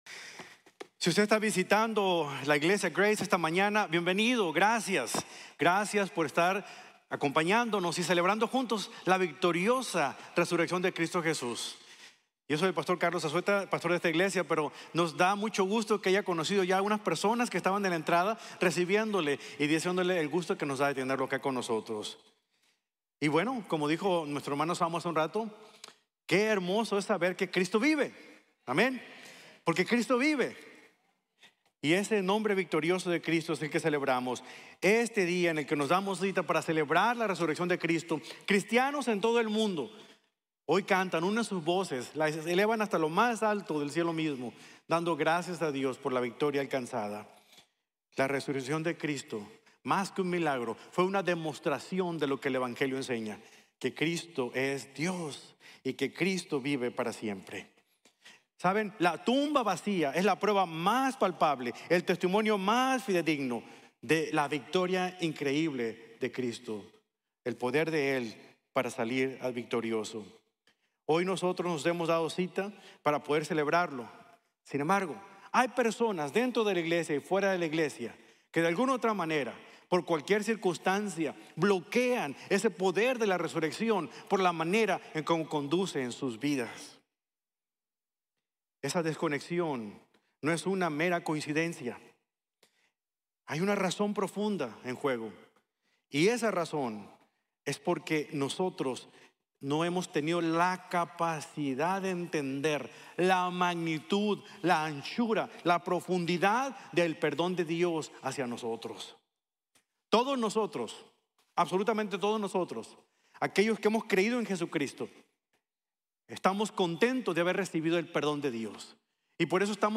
El Poder Transformador de la Resurrección | Sermon | Grace Bible Church